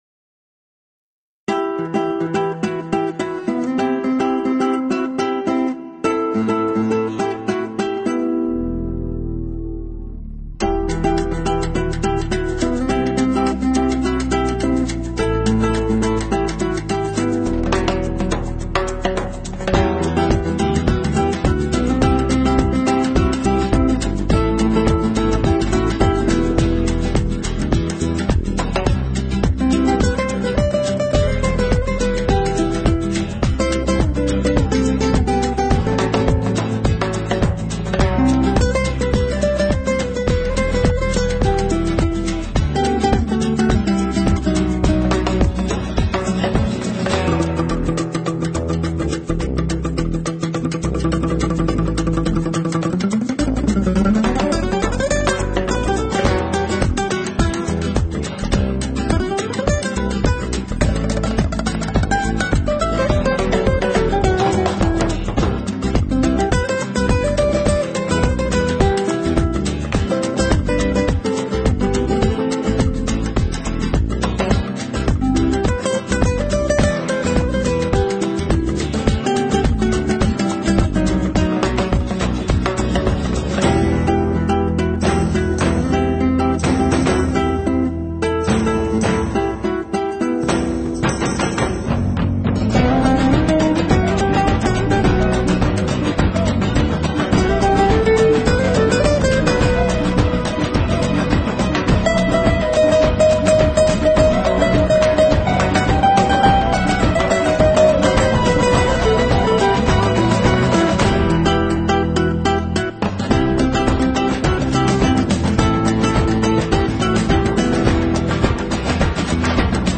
音乐类型：flamenco 弗拉明戈
音乐风格：NEWAGE.FLAMENCO